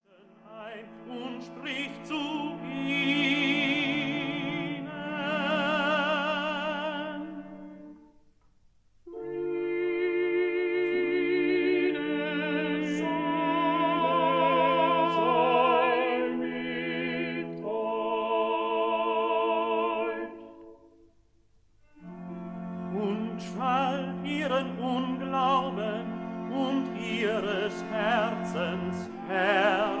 This is a 1958 stereo recording
tenor
soprano
baritone
viola da gamba
recorder
cello
double bass
positive organ